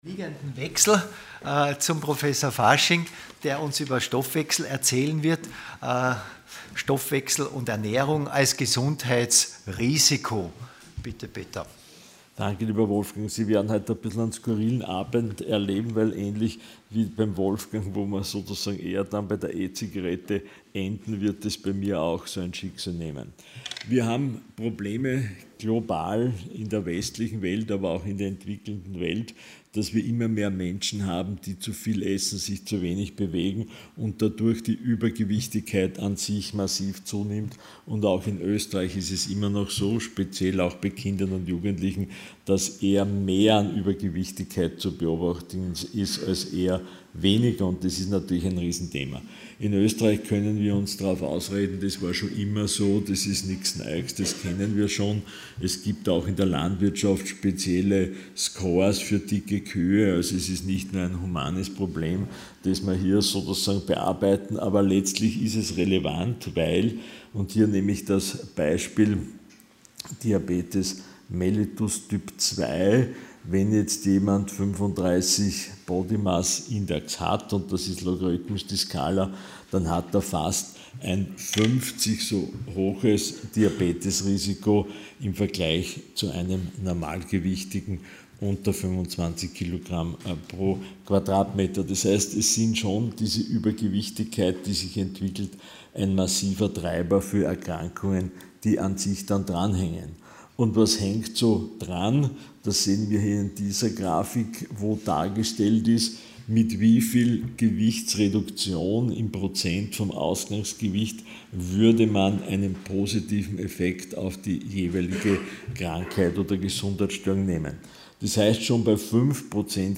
Sie haben den Vortrag noch nicht angesehen oder den Test negativ beendet.
Ort: Fach: Public Health Art: Fortbildungsveranstaltung Thema: - Veranstaltung: Hybridveranstaltung | Gesundheitsbewusstsein, Gesundheitskompetenz: was soll die ÄrztInnenschaft den PatientInnen vermitteln Moderation: